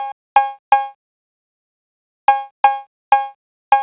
cch_percussion_loop_bashmore_125.wav